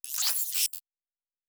pgs/Assets/Audio/Sci-Fi Sounds/Weapons/Additional Weapon Sounds 5_5.wav at master
Additional Weapon Sounds 5_5.wav